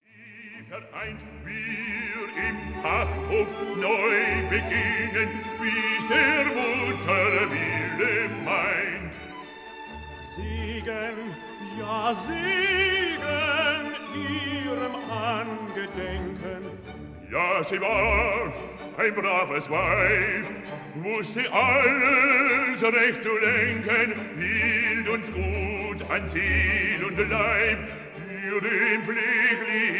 Stereo recording made in Berlin May 1960